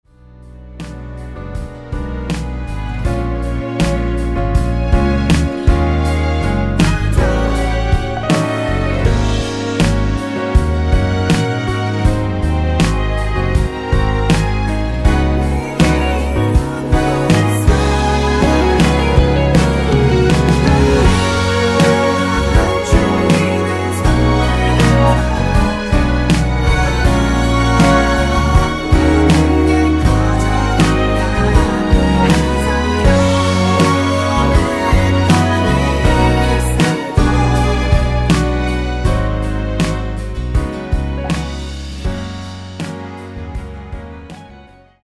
원키에서(+2) 올린 코러스 포함된 MR 입니다.(미리듣기 참조)
F#
앞부분30초, 뒷부분30초씩 편집해서 올려 드리고 있습니다.
중간에 음이 끈어지고 다시 나오는 이유는